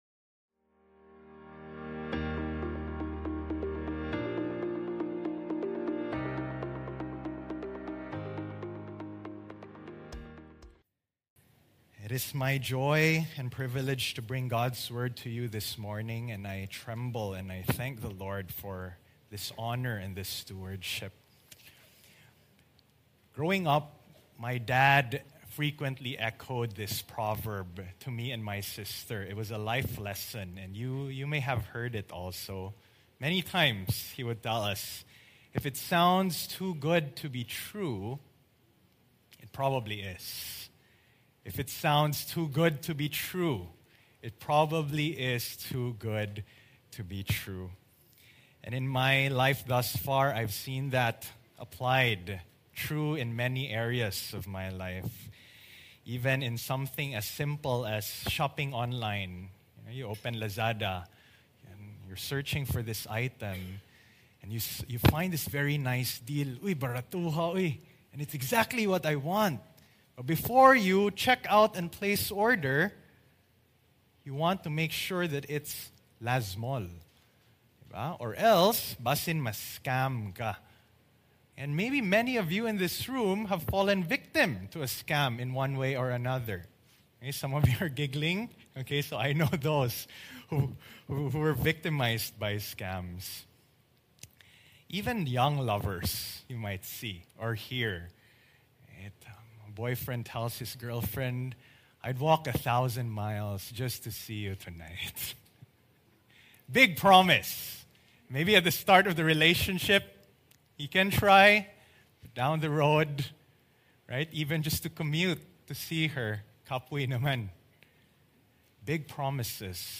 Galatians 4:21-31 Sermon